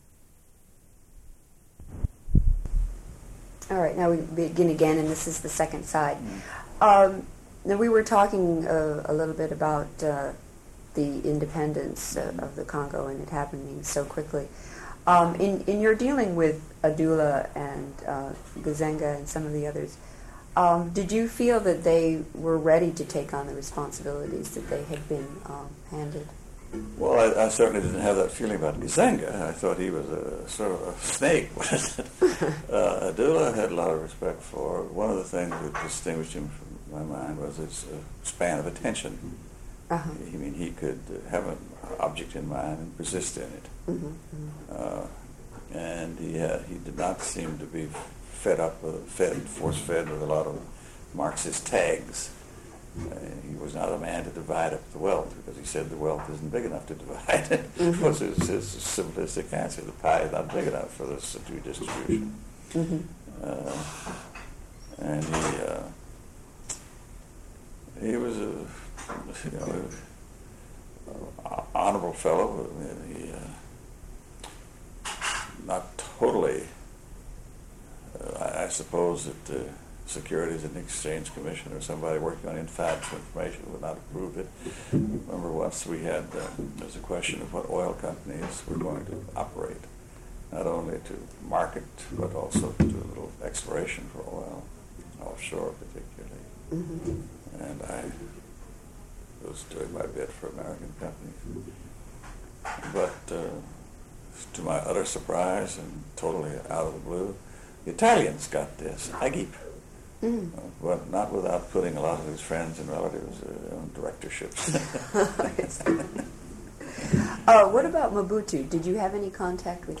Interview with Edmund Gullion /